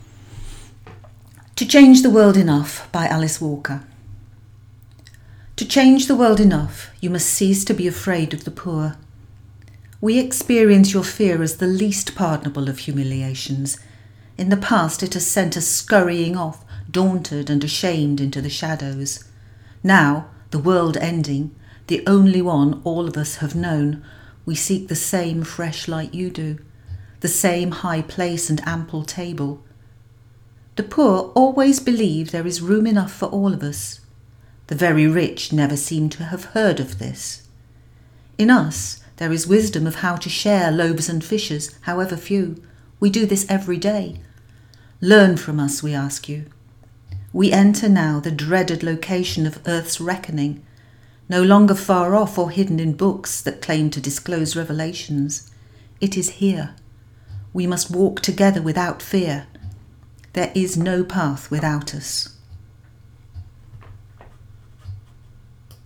She donated this recording because she knows it is so helpful for our learners to hear native English speakers.